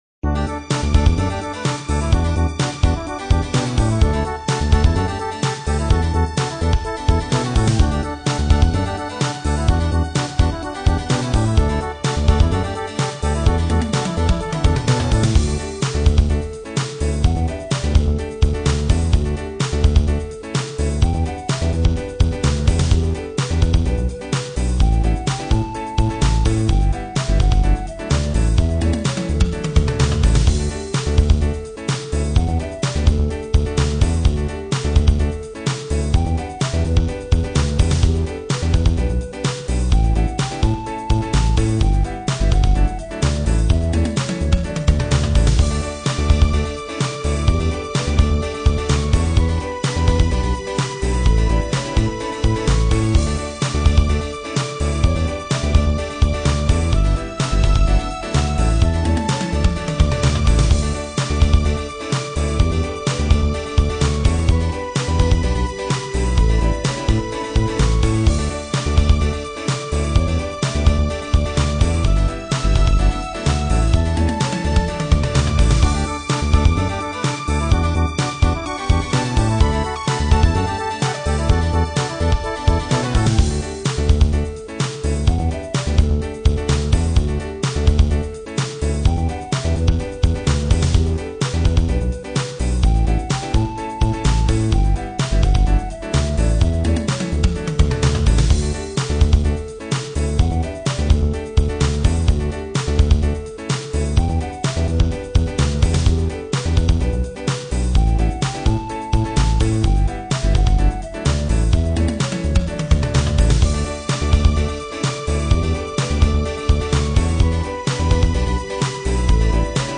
Главная » Файлы » Минусовки » минусы Қазақша